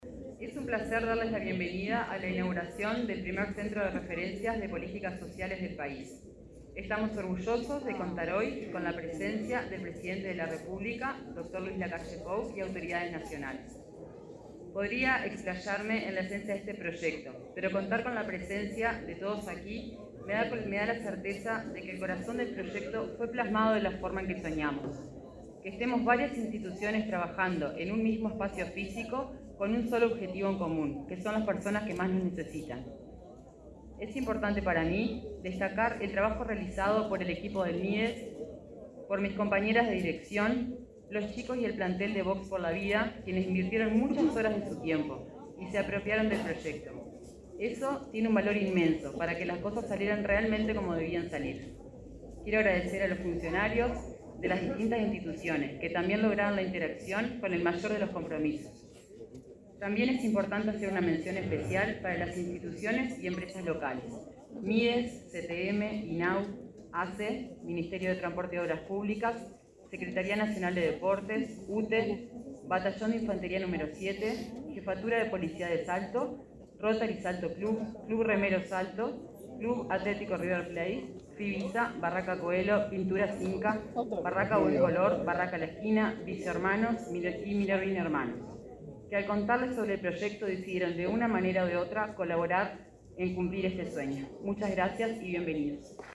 Palabras de la directora departamental del Mides en Salto